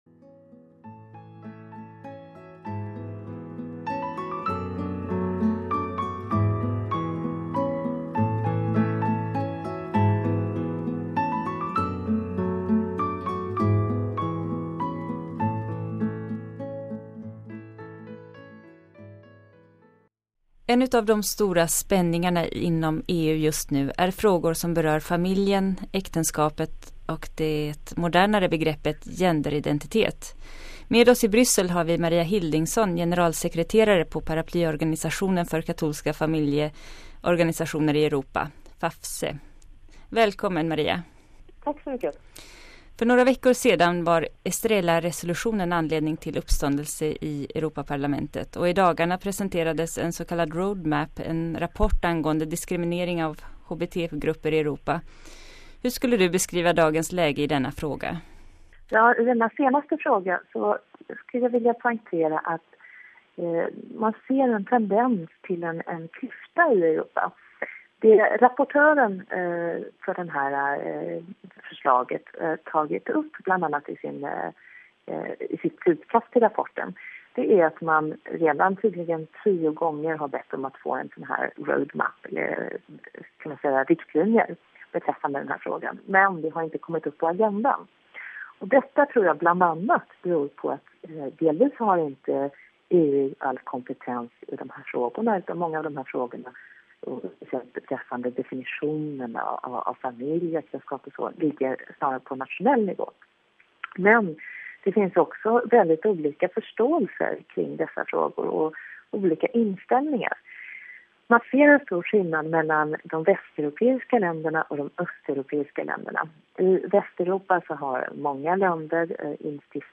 Hur står de europeiska medborgarna i dessa frågor, vilka driver dem och finns det dolda agendor? Lyssna på intervjun här: RealAudio